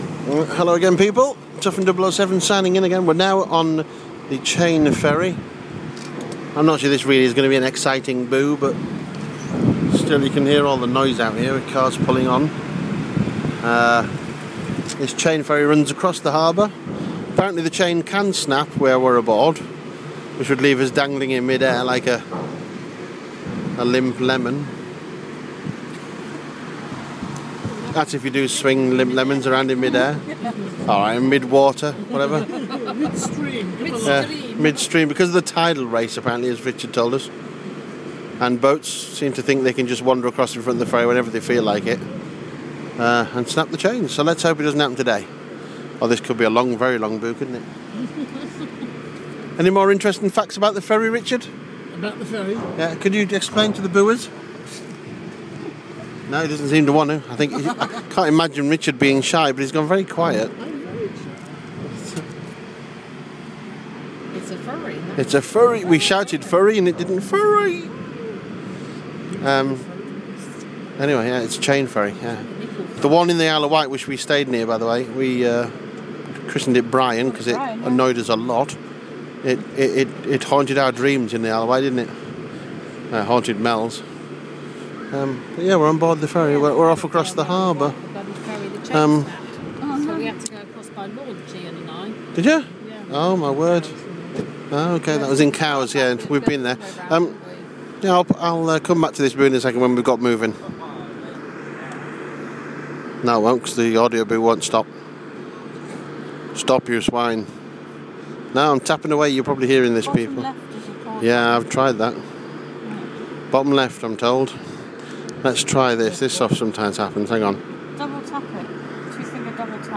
Chain ferry crossing